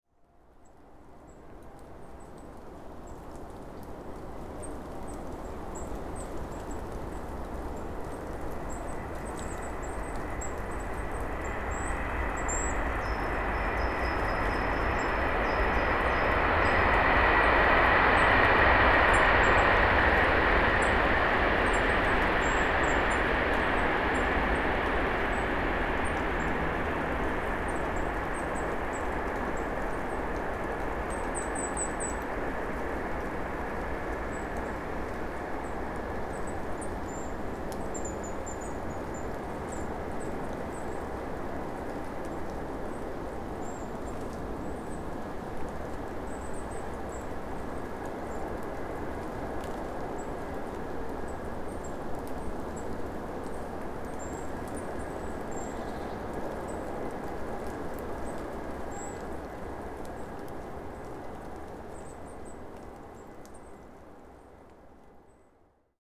PFR08713, 111130, Goldcrests Regulus regulus in their natural habitat, social calls, Oberholz, Germany